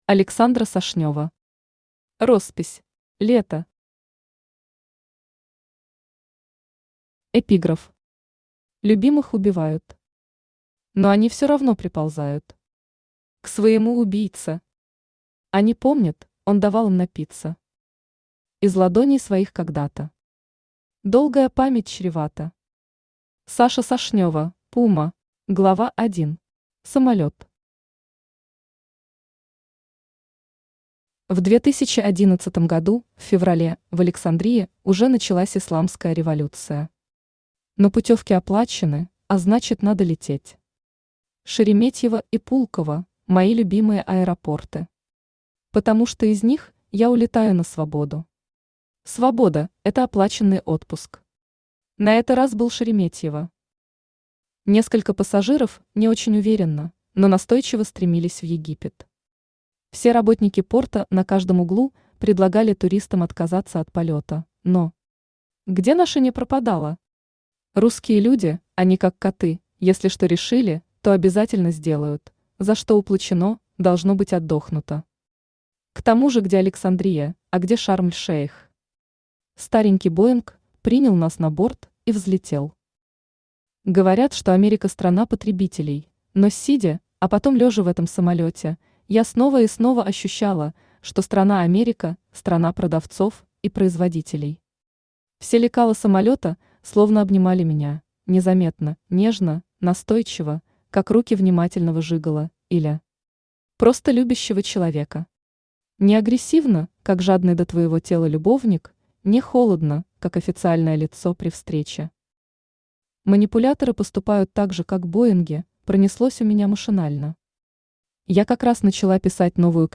Aудиокнига Роспись. Лето Автор Александра Сашнева Читает аудиокнигу Авточтец ЛитРес.